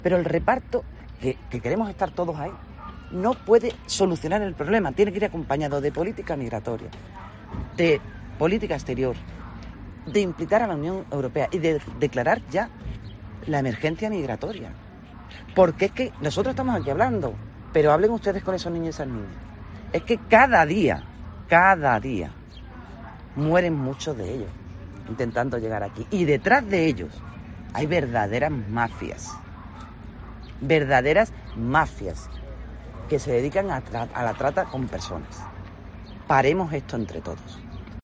Loles López, consejera de Inclusión Social, Juventud, Familias e Igualdad de la Junta de Andalucía